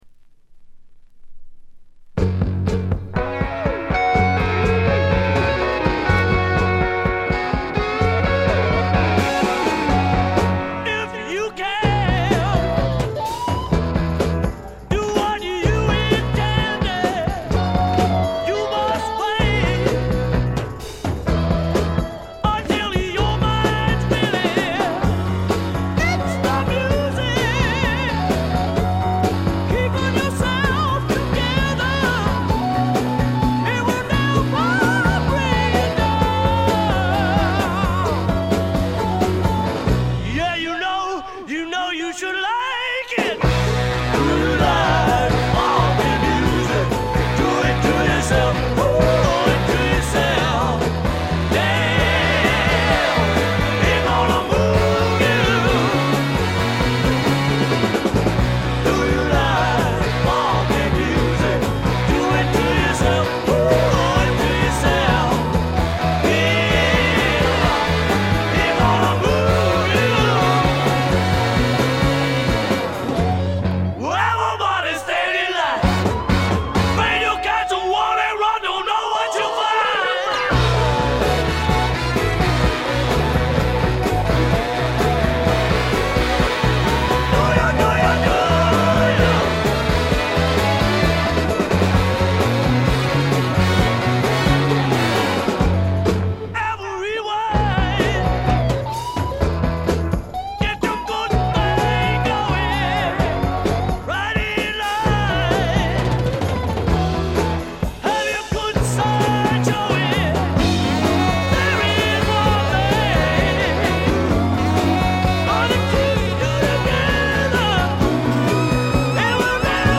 わずかなノイズ感のみ。
泣けるバラードからリズムナンバーまで、ゴスペル風味にあふれたスワンプロック。
試聴曲は現品からの取り込み音源です。